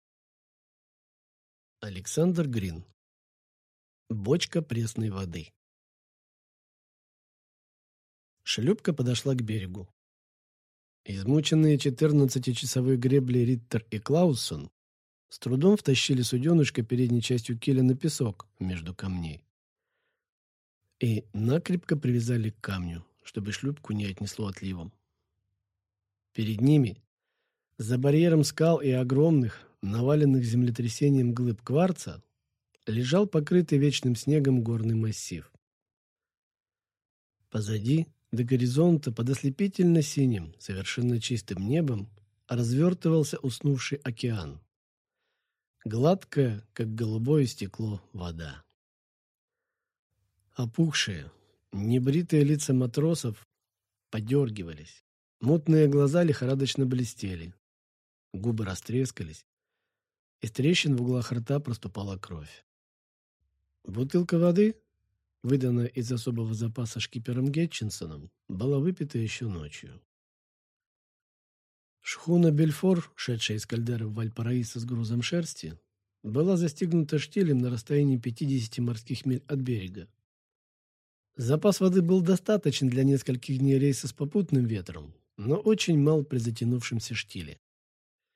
Аудиокнига Бочка пресной воды | Библиотека аудиокниг